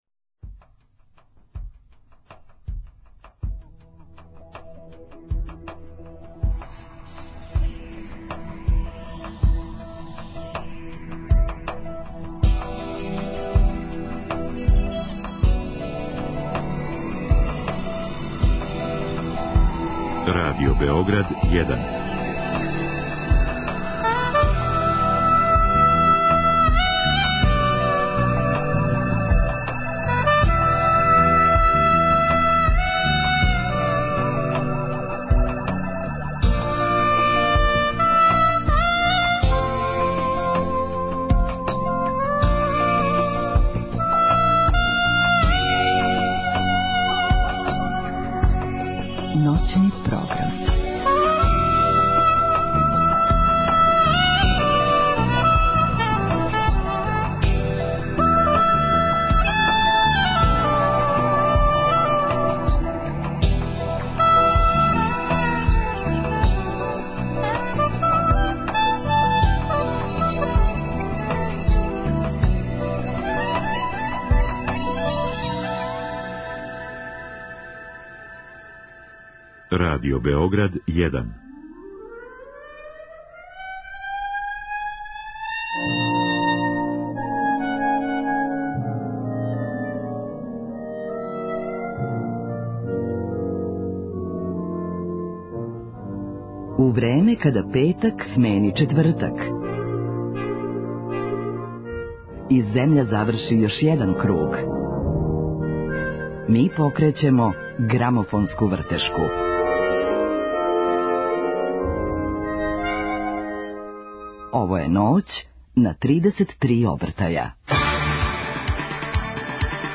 Гост Ноћи на 33 обртаја биће писац и музичар Марко Шелић Марчело. Разговараћемо о његовој музици, друштвеном активизму, али и о његовој новој књизи коју је скоро објавио.